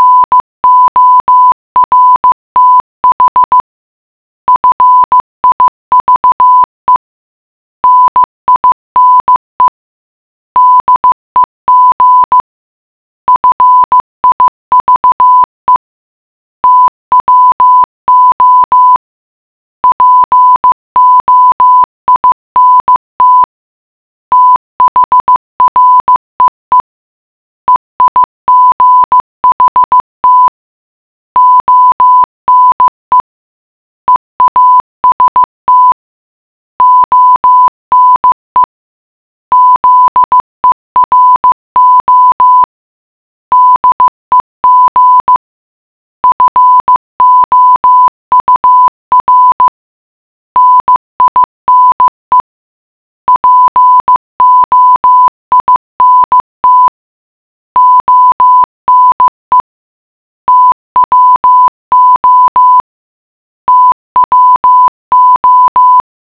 15 words/min (normal speed) or
Morse alphabet
morse15.wav